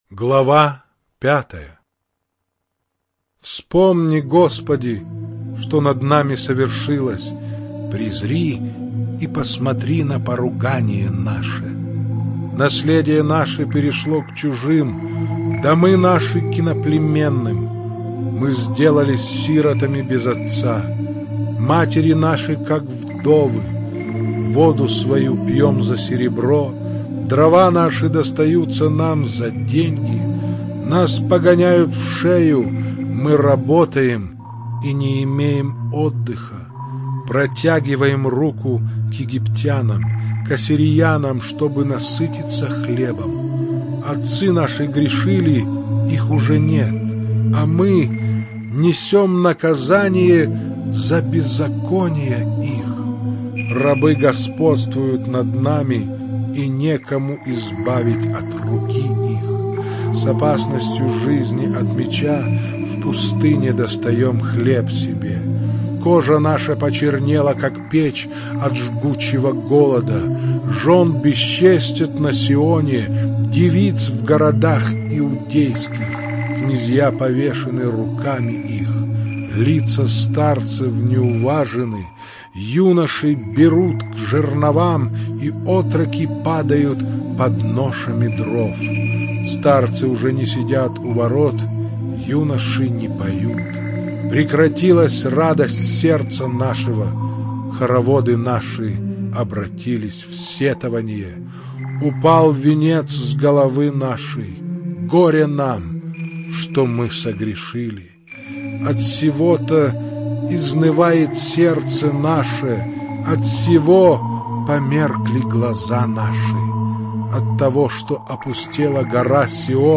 Аудио Библия - Слушать ветхий завет онлайн mp3
Чтение сопровождается оригинальной музыкой и стерео-эффектами